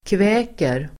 Ladda ner uttalet
kväka verb, croakGrammatikkommentar: x &Uttal: [kv'ä:ker] Böjningar: kväkte, kväkt, kväk, kväka, kväkerDefinition: låta som en groda